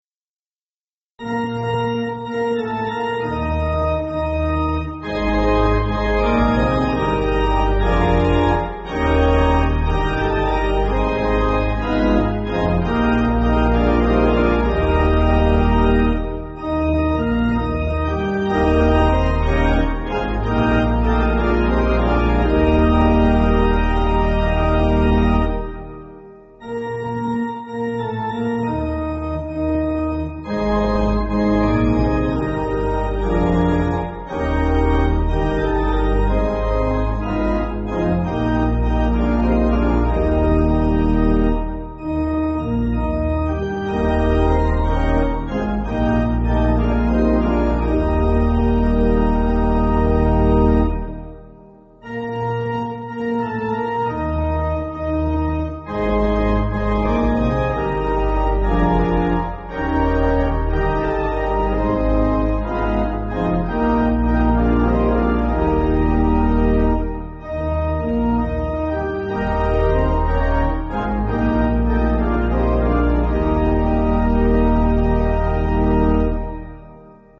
(CM)   4/Eb